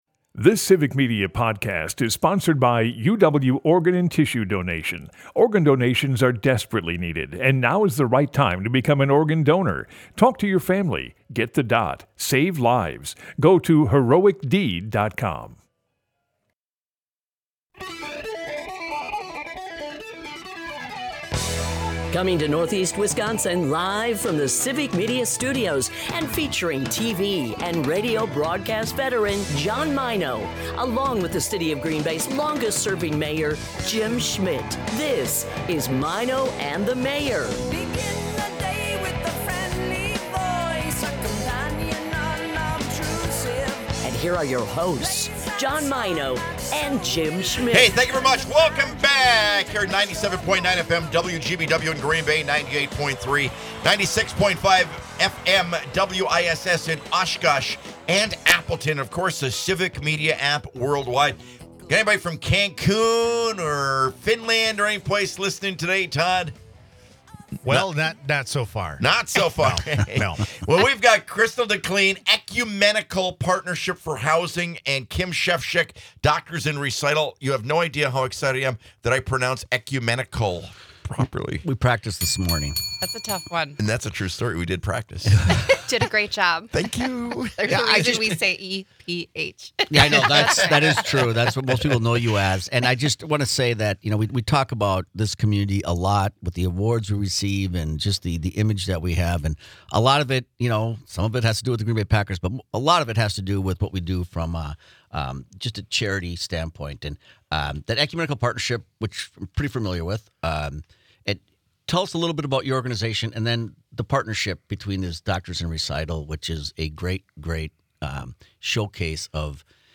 A family opportunity mortgage is a loan for a residential property bought for a parent or an adult child student or a special needs adult child who could not qualify for financing on their own. Maino and the Mayor is a part of the Civic Media radio network and airs Monday through Friday from 6-9 am on WGBW in Green Bay and on WISS in Appleton/Oshkosh.